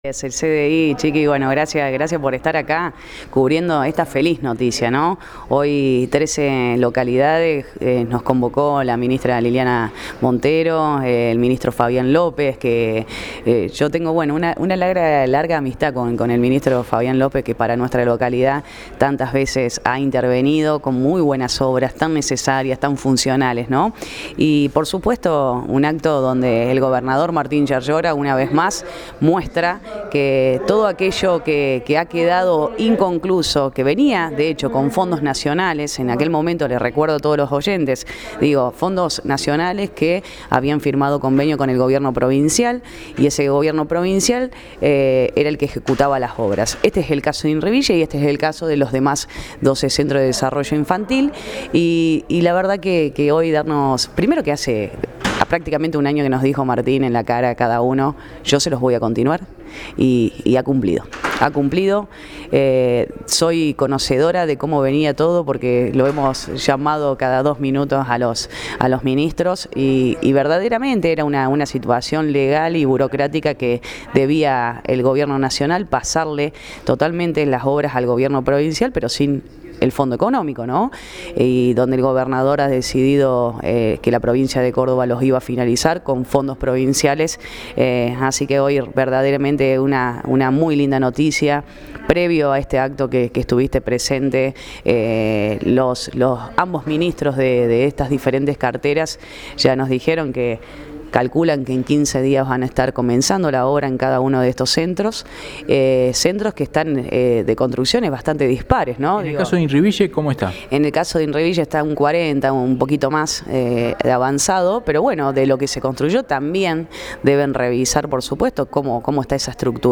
La intendenta de Inriville, Julieta Aquino, en diálogo con GEF Informa sostuvo «Estos Centros de Desarrollos Infantiles era lo que nos faltaba en Inriville para poder completar el ciclo del estudiante.
Audio: Julieta Aquino (Intendenta de Inriville)